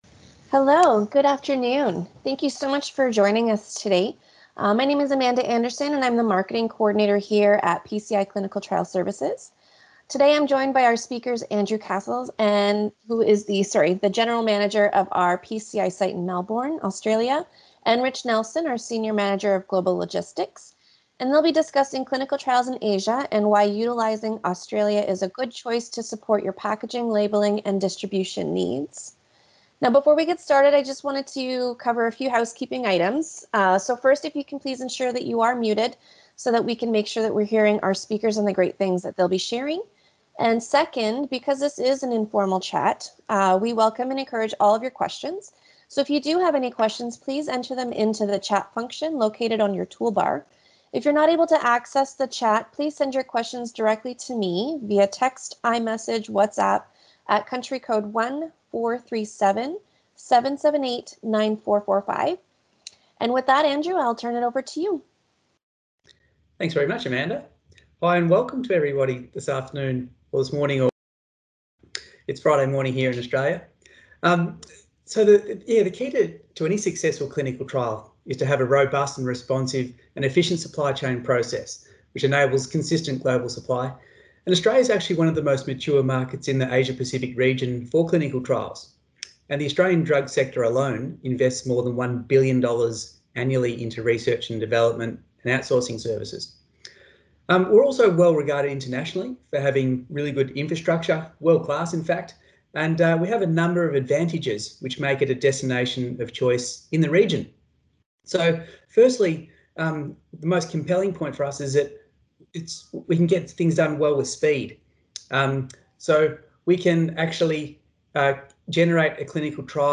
Download and listen to our experts